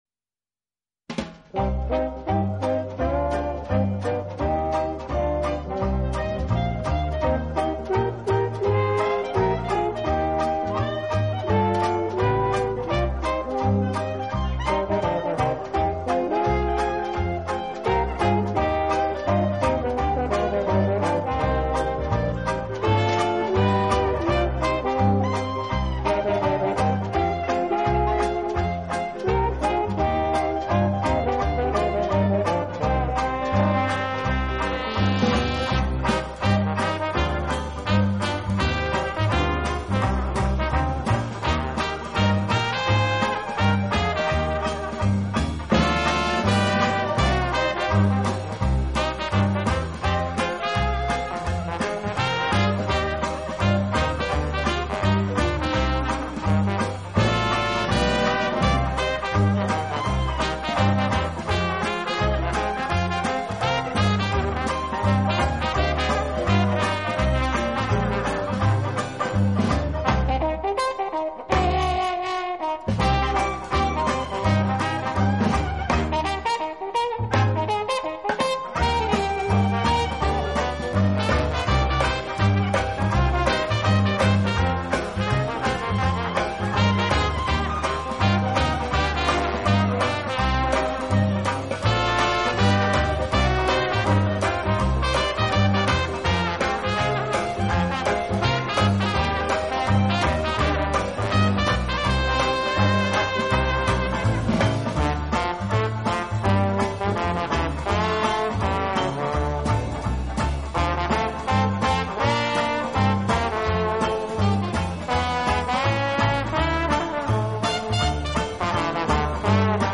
Genre: Instrumental, Classical